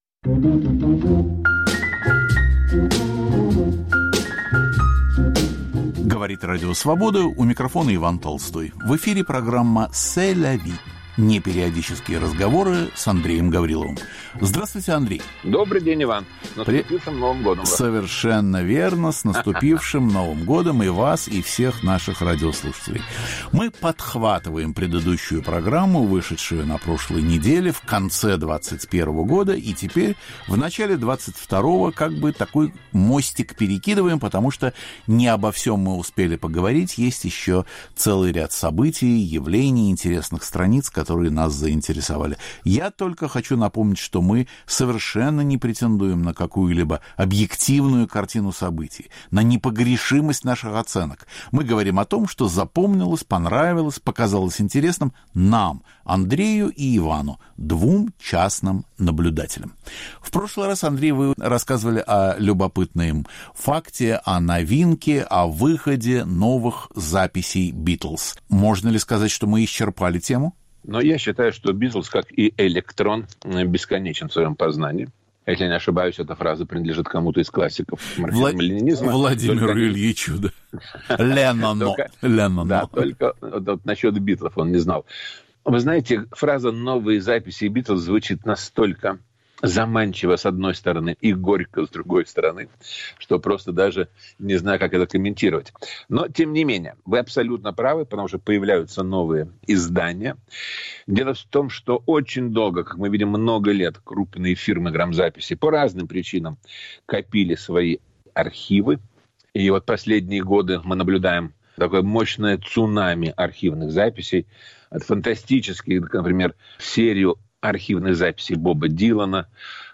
Ведущий Иван Толстой поднимает острые, часто болезненные, вопросы русского культурного процесса: верны ли устоявшиеся стереотипы, справедливы ли оценки, заслуженно ли вознесены и несправедливо ли забыты те или иные деятели культуры?